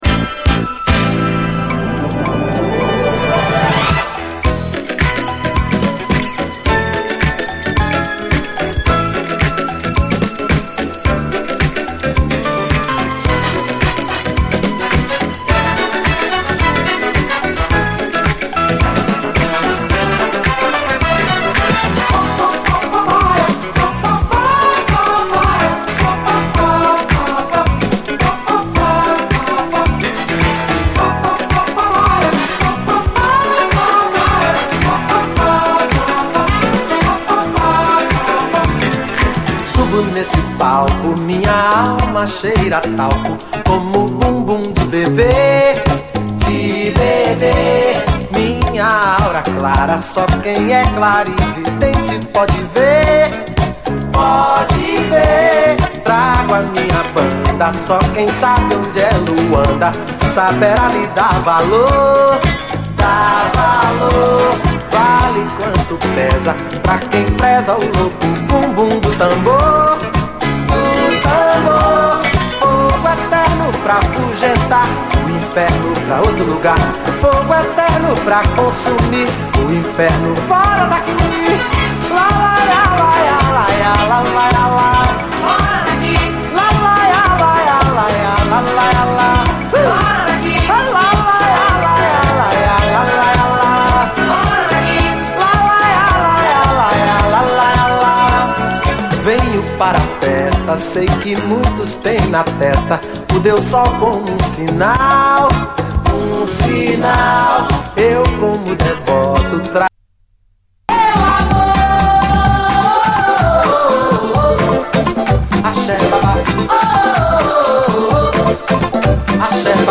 BRAZIL
ブラジリアン・ソウル〜ディスコな内容の81年作！
無条件でハッピーになってしまう一曲です！